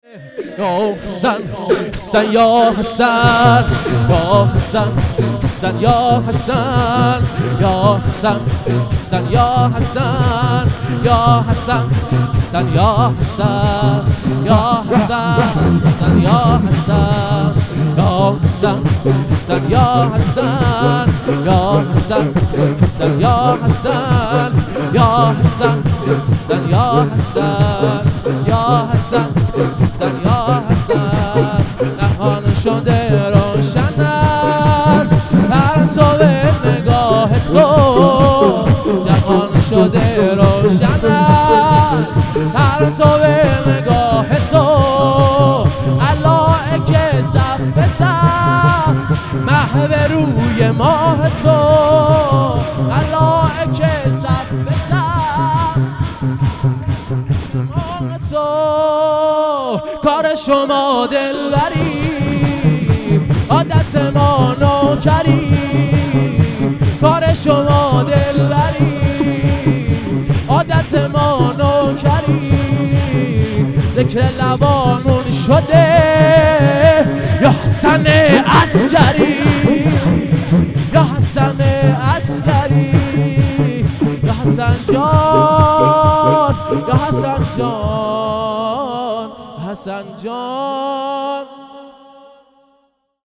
جشن میلاد حضرت عبدالعظیم حسنی (ع) و امام حسن عسکری (ع) | مسجد امام موسی بن جعفر (ع) | 23 آذرماه 1397